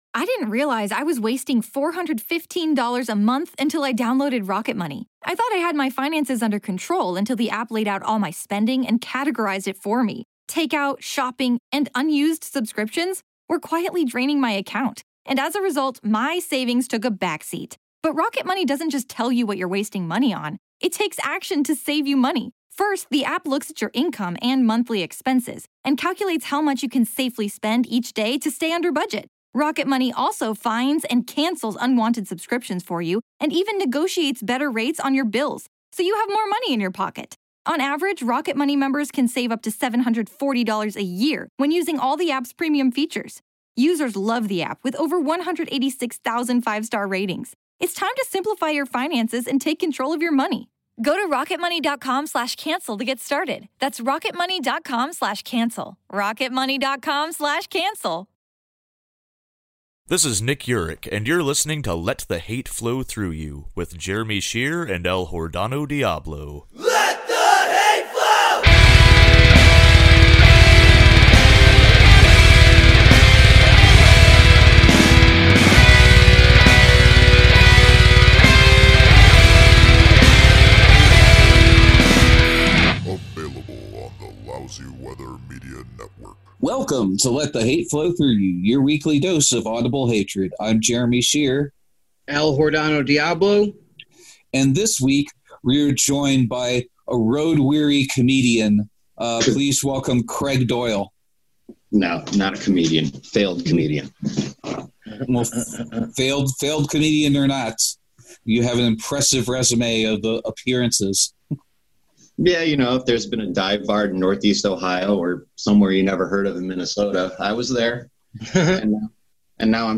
Plus, what's going on with the reopening of comedy clubs during the pandemic. Recorded with Zoom.